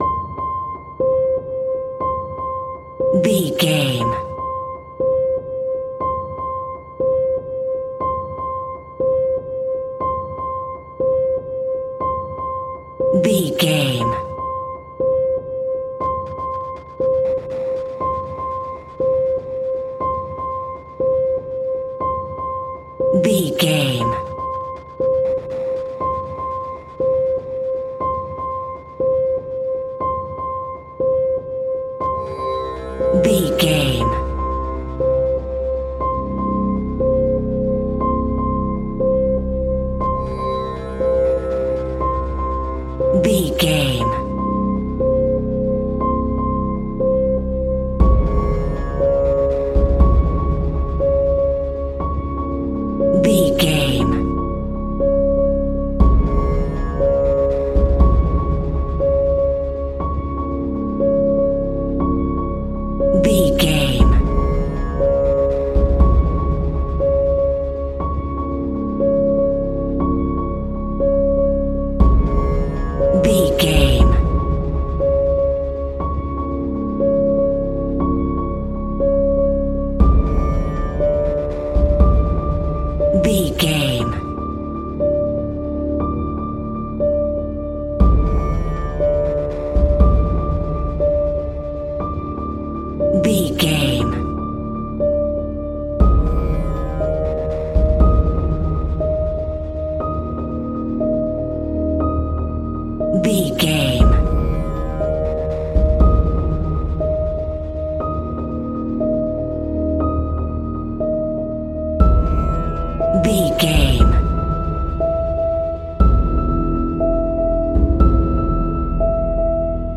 Thriller
Aeolian/Minor
scary
tension
ominous
dark
suspense
haunting
eerie
piano
percussion
mysterious